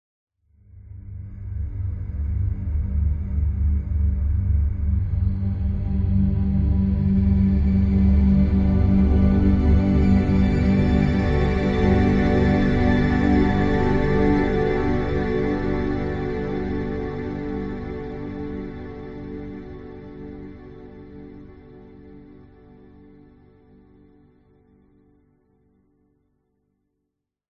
Эпичный и кинематографический восход солнца на рассвете